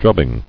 [drub·bing]